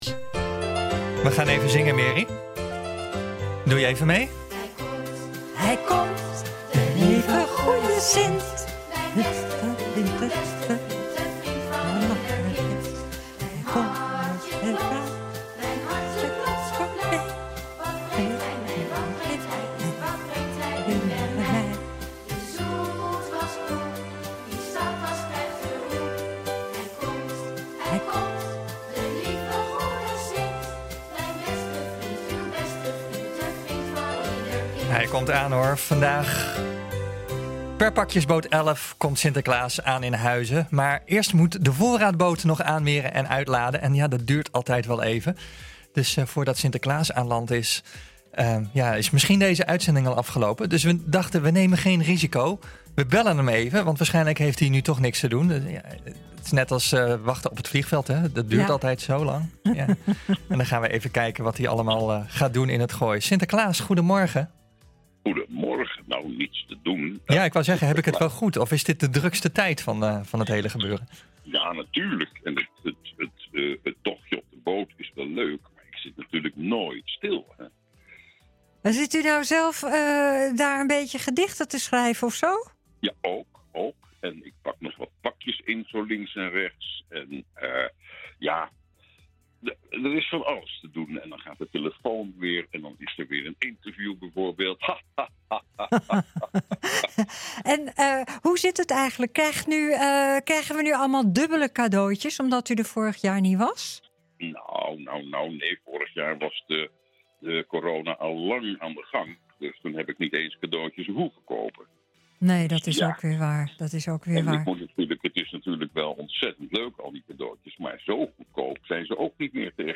NH Gooi Zaterdag - Een gesprek met Sinterklaas voordat hij aankomt in Huizen
Vandaag komt Sinterklaas per pakkjesboot 15 aan in Huizen. maar eerst moet de voorraadboot nog aanmeren en uitladen, dus het duurt nog wel even voordat Sinterklaas aan land is, dus we nemen geen risico en bellen hem even, hij zit nu toch maar te wachten, dan vragen we wat hij allemaal gaat doen in het Gooi.